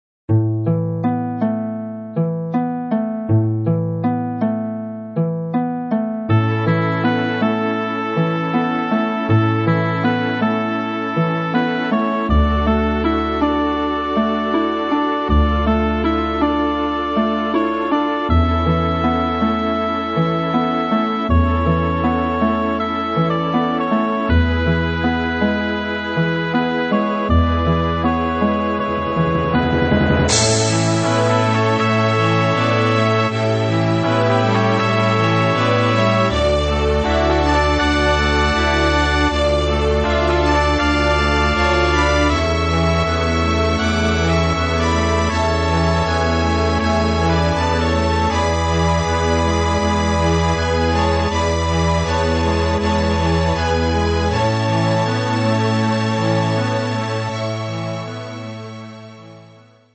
お遊びなので、パート数も最低限で、音色やボリュームも適当です。
（ヘッドホンで音量調整しているのでスピーカーで聴くと低音が小さいはずです・・・）
026 　のどかな夕暮れ（A） 06/11/20